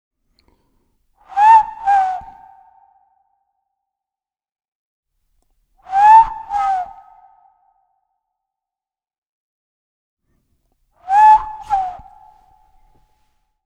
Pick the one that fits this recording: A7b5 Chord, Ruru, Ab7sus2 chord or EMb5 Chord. Ruru